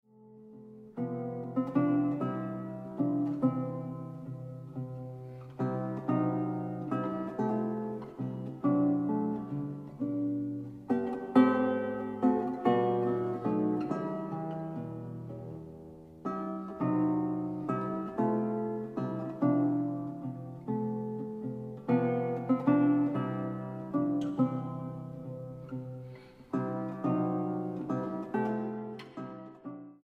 guitarra
Andante 2.57